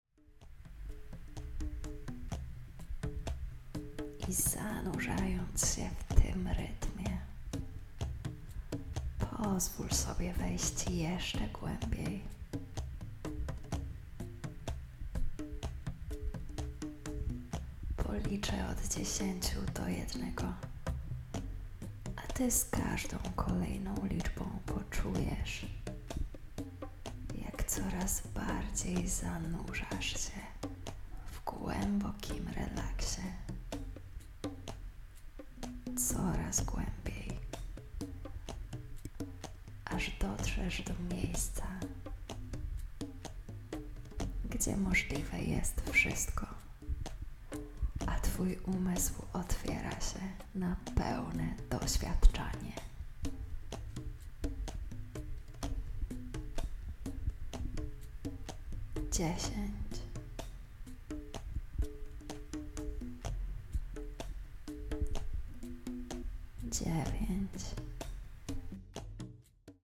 Ta nieco ponad 40-minutowa medytacja autohipnozy pozwoli Ci odkryć i rozniecić Twój wewnętrzny ogień, który przyciąga, zniewala i inspiruje.